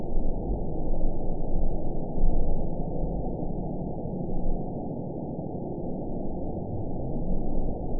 event 920757 date 04/07/24 time 23:56:25 GMT (1 year, 8 months ago) score 7.87 location TSS-AB02 detected by nrw target species NRW annotations +NRW Spectrogram: Frequency (kHz) vs. Time (s) audio not available .wav